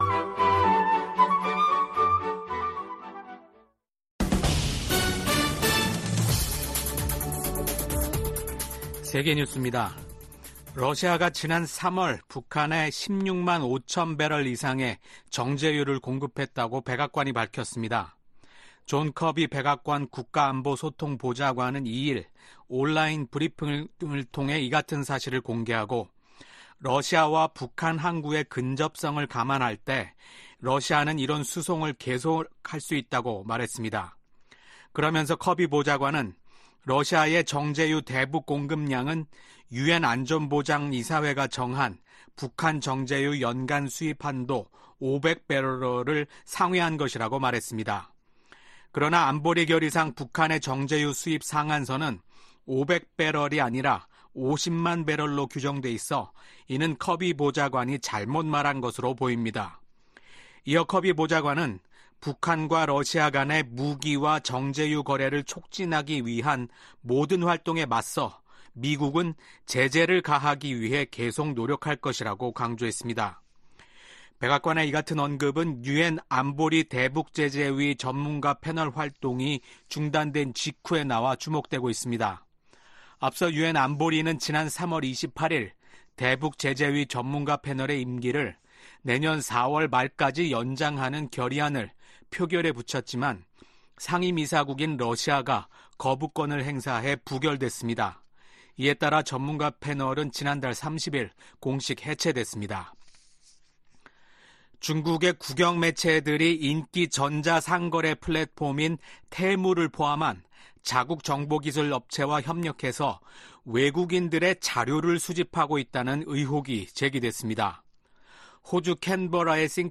VOA 한국어 아침 뉴스 프로그램 '워싱턴 뉴스 광장' 2024년 5월 3일 방송입니다. 미국, 한국, 일본 등 50개국이 대북제재위 전문가패널을 대체할 감시 기구 설립 방안을 검토 중이라고 밝혔습니다. 미국 국방부는 북한과 러시아, 이란 간 협력이 강화되는데 중대한 우려를 표시하며, 이를 계속 주시하고 있다고 밝혔습니다. 미국의 우주사령관이 최근 한국을 방문해 한반도 방어 태세를 강화하고 우주 분야 협력을 촉진하는 방안을 논의했습니다.